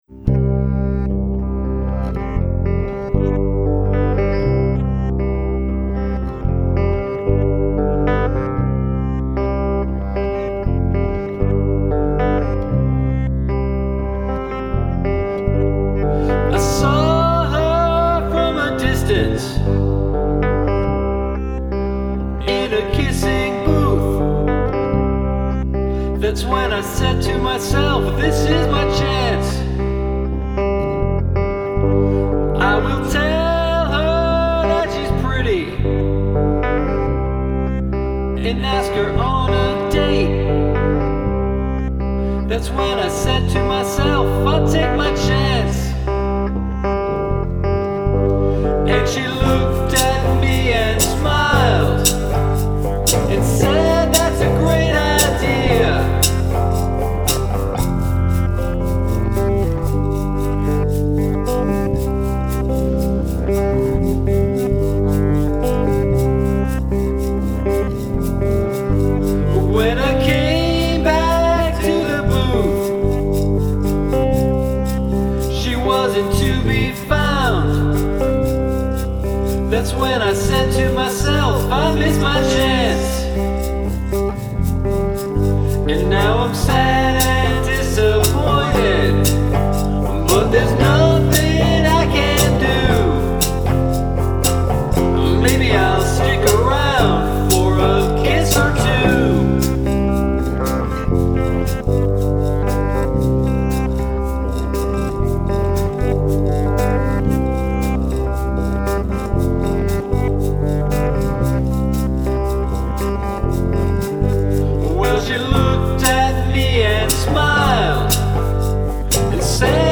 boston's power duo